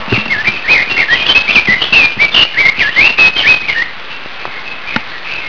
想思鳥（そうしちょう）
鳴き声
口笛を吹くような澄んだ声が朗々と、キビタキでもないし、何かの変わり鳴き？